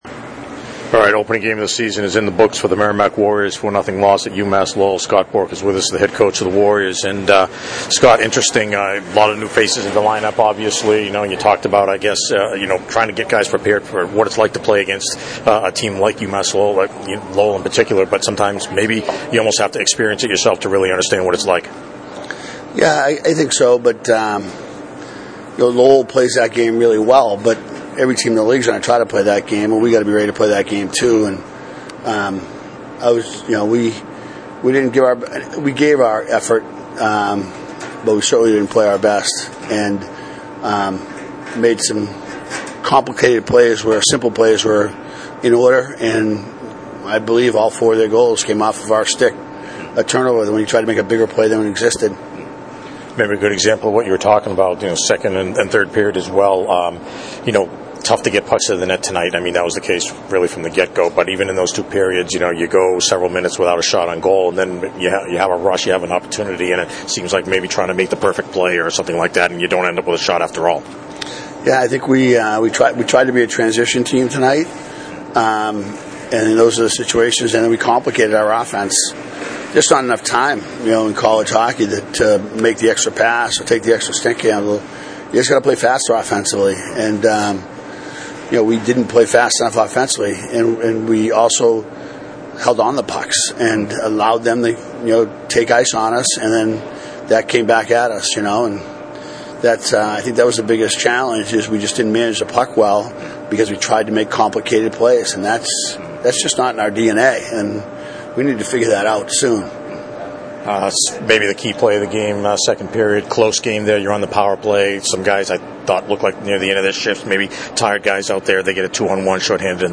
Press Conferences